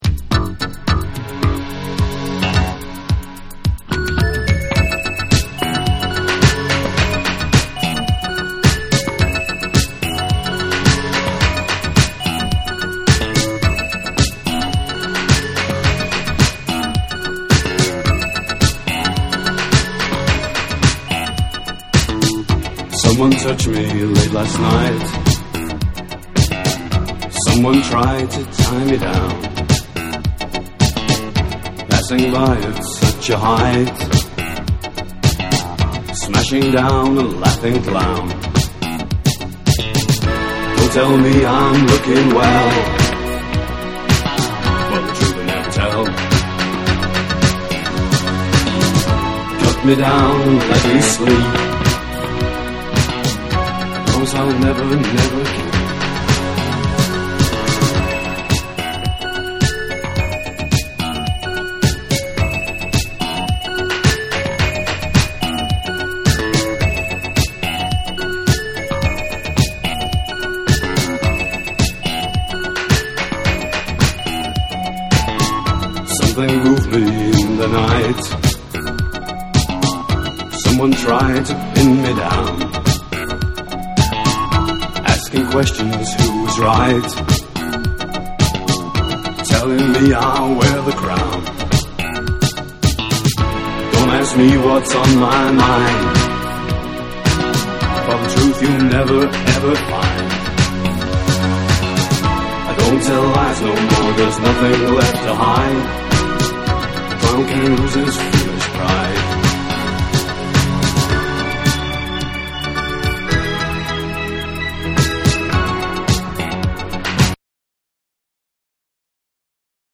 NEW WAVE & ROCK